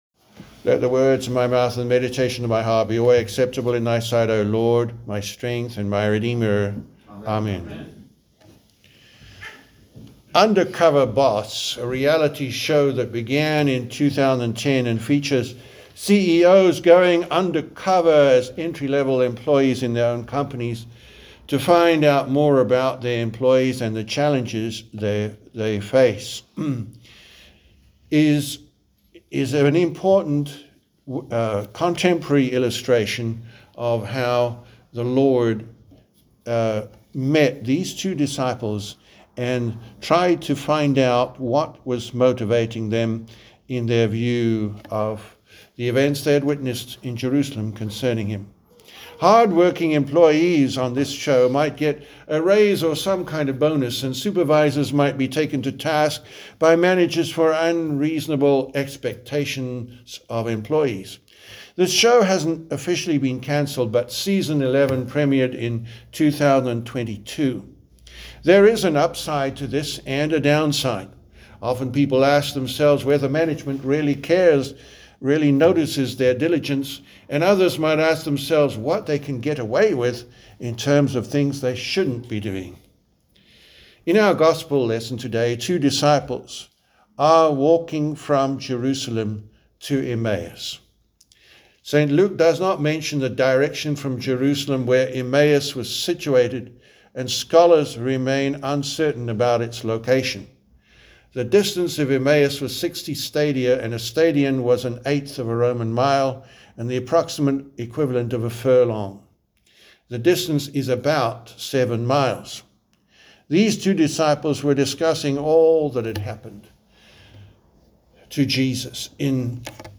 The Sermon for Sunday, April 19th, 2026, the Second Sunday after Easter